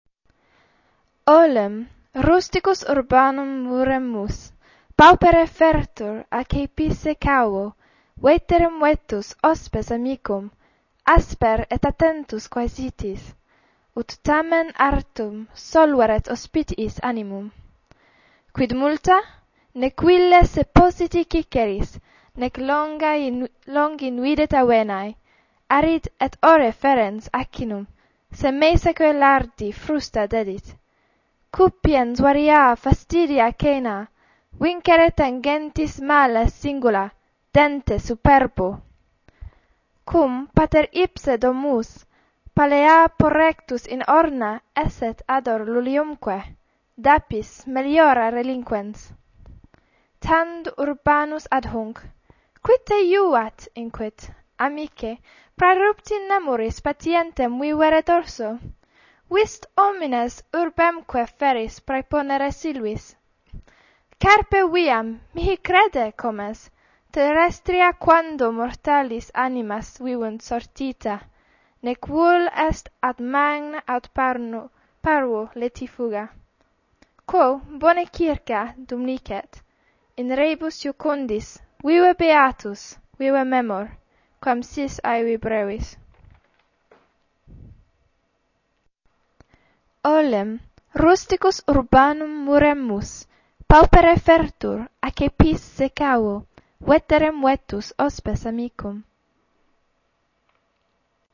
Spoken Latin